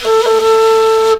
FLUTELIN07.wav